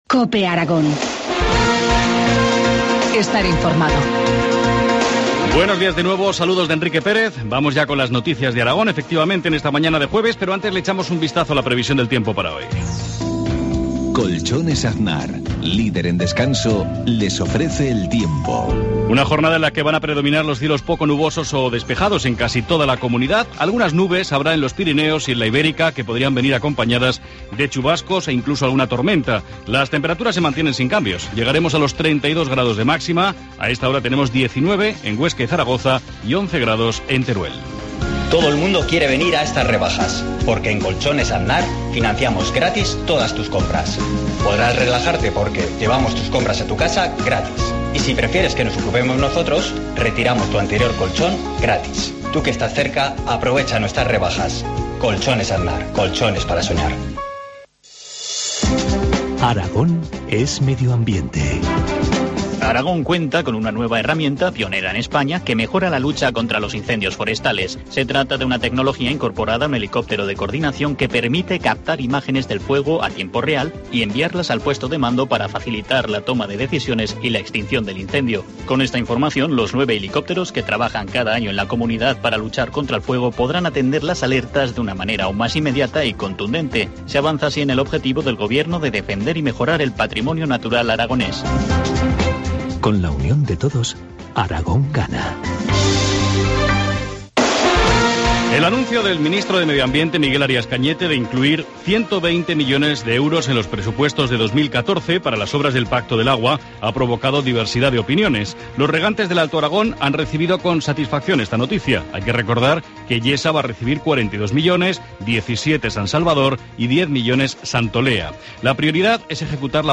Informativo matinal, jueves 5 de septiembre, 7.53 horas